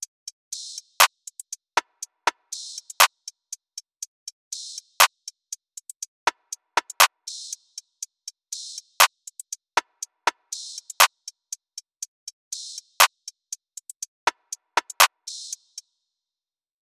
EN - Loop I (120 BPM).wav